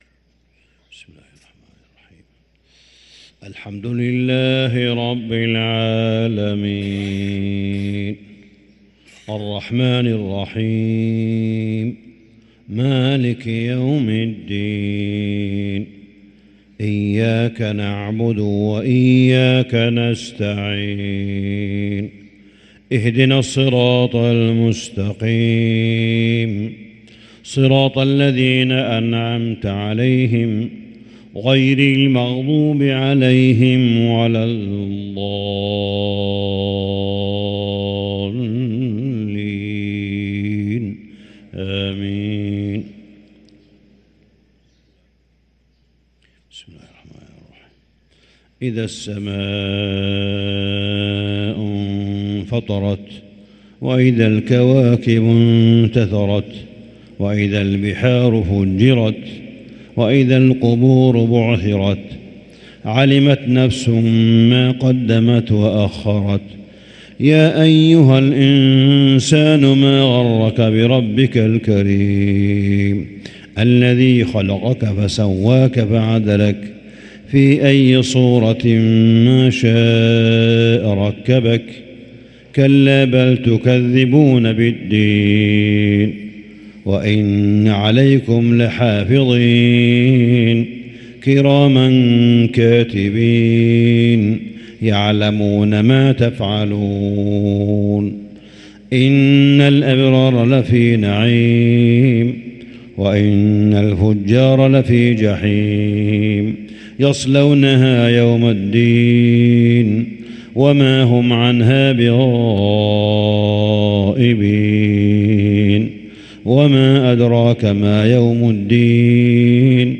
صلاة الفجر للقارئ صالح بن حميد 29 رمضان 1444 هـ
تِلَاوَات الْحَرَمَيْن .